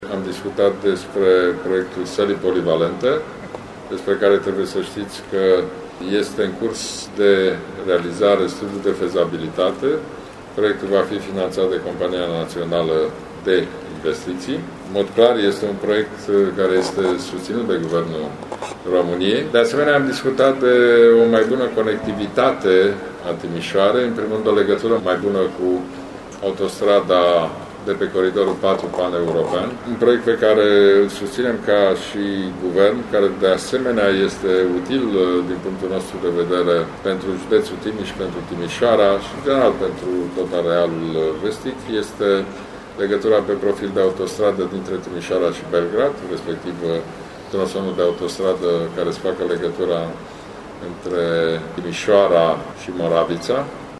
Prezent la Timișoara, unde a vizitat și șantierul centurii de Sud, Ludovic Orban a declarat că Guvernul va sprijini construirea unei noi conexiuni cu autostrada A1.